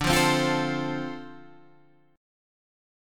Dm7 Chord
Listen to Dm7 strummed